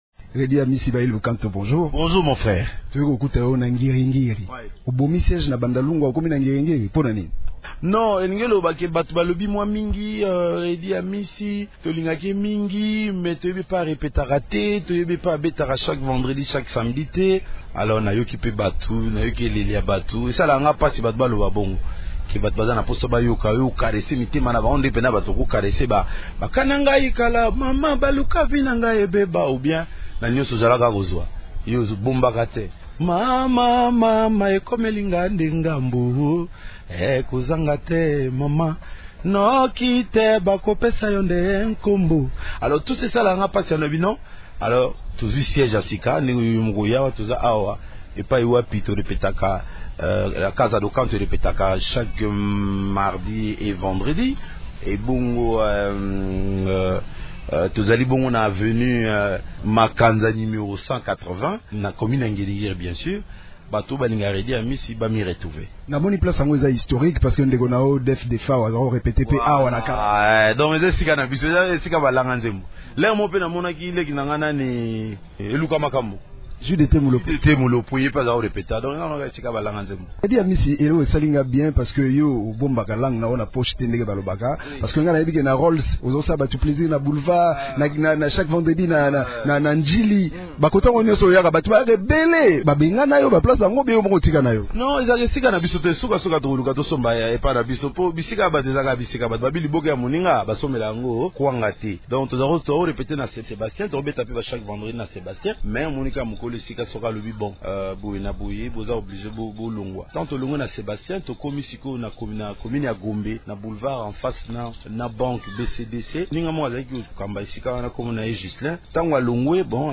Entretien à bâtons rompus